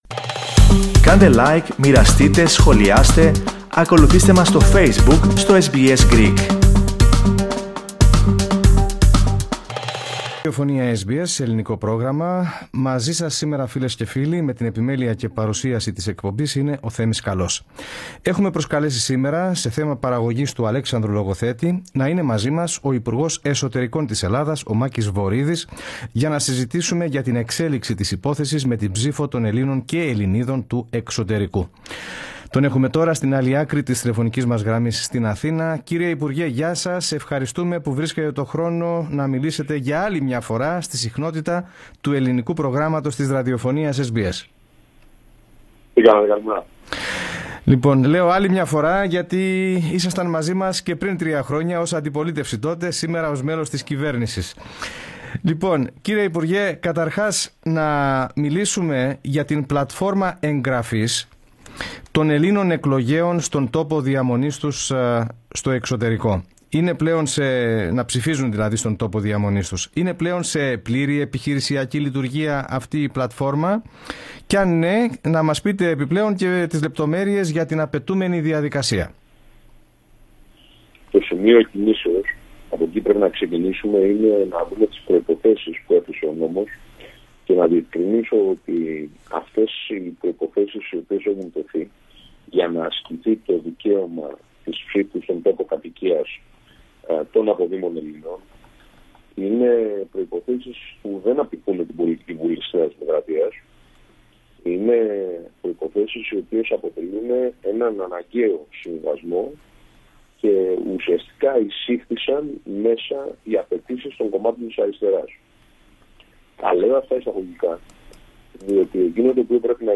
Αυτό, τόνισε, μεταξύ άλλων, ο υπουργός Εσωτερικών της Ελλάδας, Μάκης Βορίδης, ο οποίος μίλησε αποκλειστικά στο Ελληνικό Πρόγραμμα της ραδιοφωνίας SBS Greek.